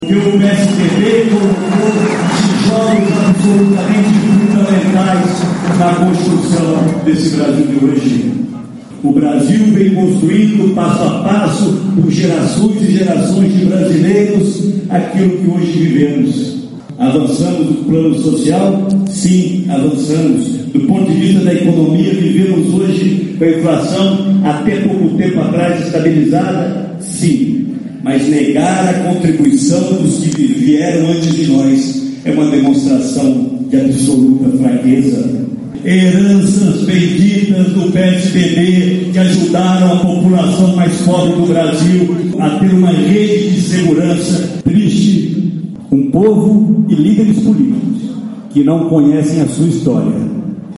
O senador Aécio Neves abriu o seminário “Discutindo o futuro do Brasil”, nesta segunda-feira, em Goiânia, com uma homenagem às gerações de brasileiros que trabalharam para a construção do país.
Recebido com aplausos e entusiasmo, Aécio saudou o as conquistas sociais alcançadas nos governos de Itamar Franco e Fernando Henrique Cardoso, e disse que o Brasil é um país em construção.
Fala do senador Aécio Neves